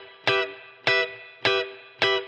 DD_TeleChop_105-Emin.wav